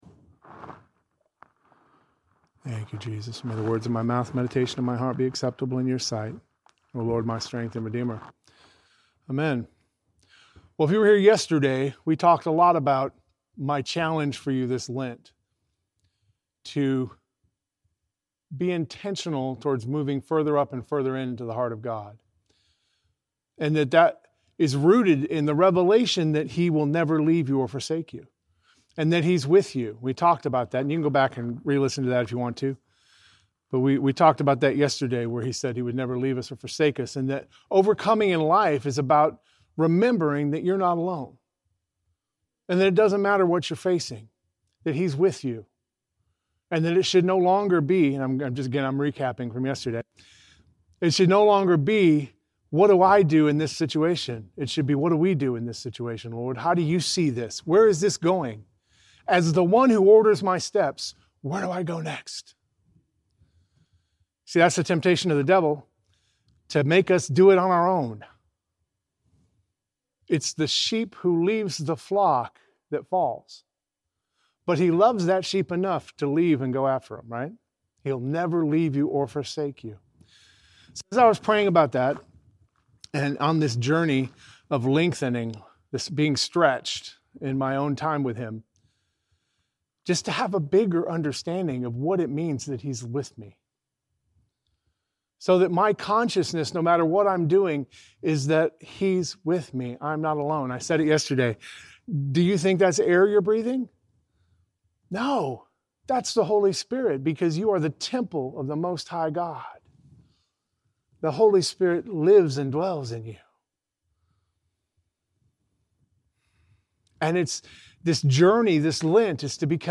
Audio Devotionals Service Type: Devotional Share this